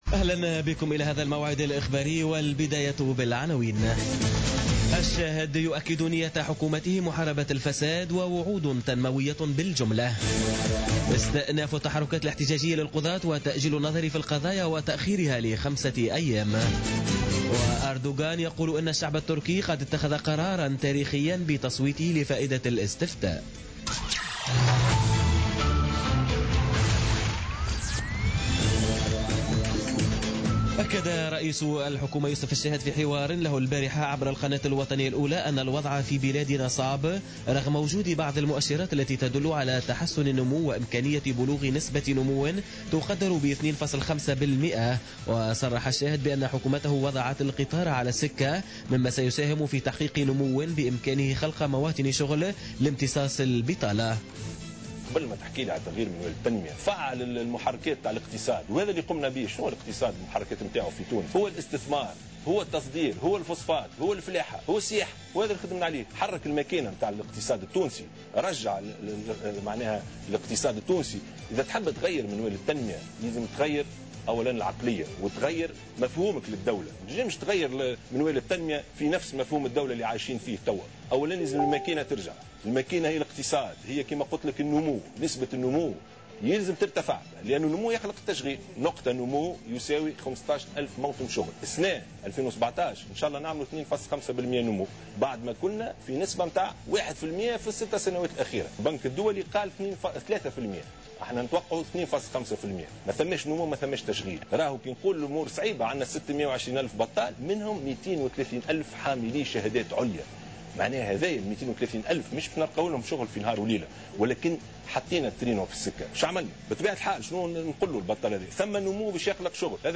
نشرة أخبار منتصف الليل ليوم الاثنين 17 أفريل 2017